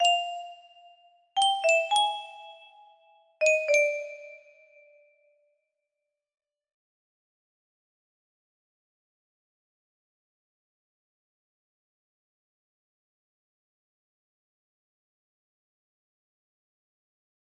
a music box melody